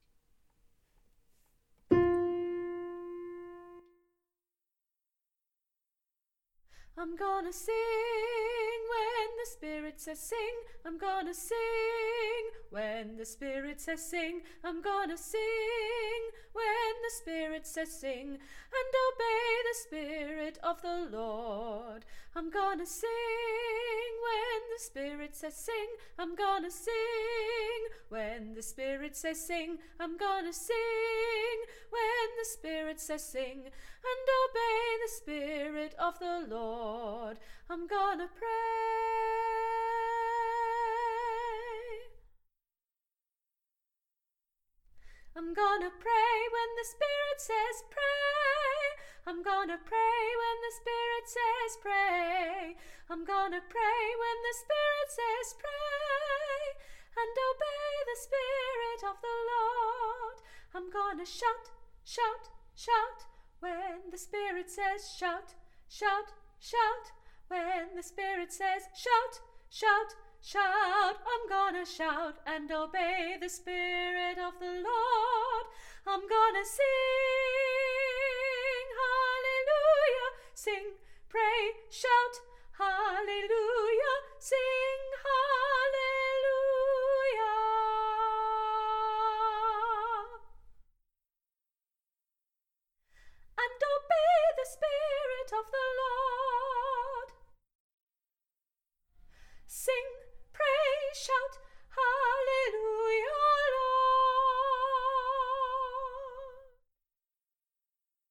Bass I’m Gonna Sing
Bass-Im-Gonna-Sing.mp3